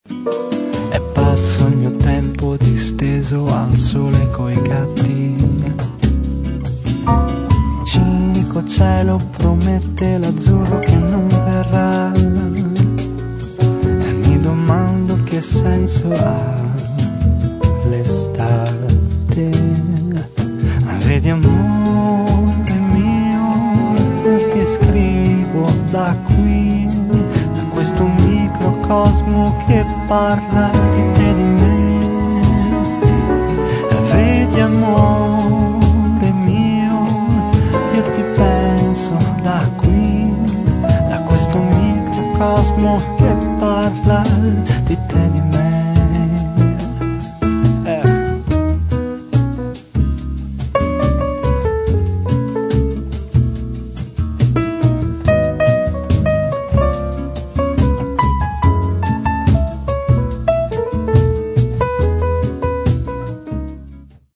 registrato e missato alla
chitarre acustiche e classiche